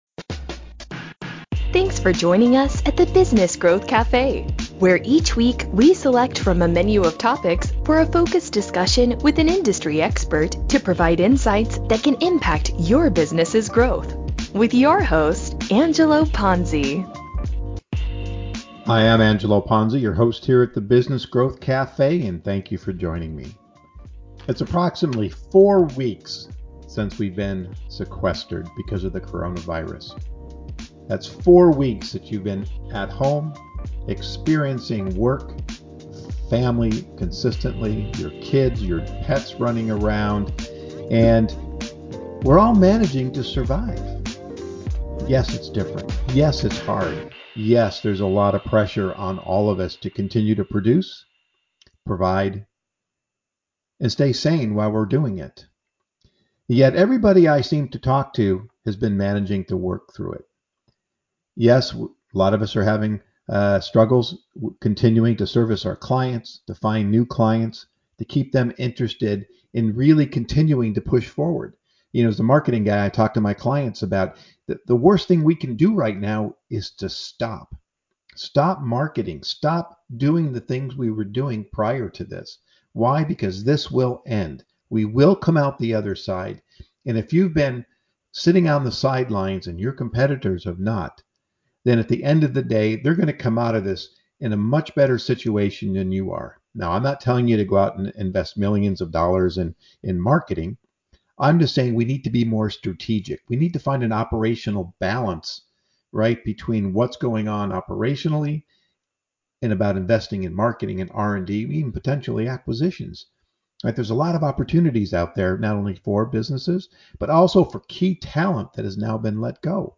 To better understand what is happening to business owners in real-time, I conducted interviews with six different business leaders from six different industries. The goal was to gain insights for listeners, to help better understand how others are coping with the new normal.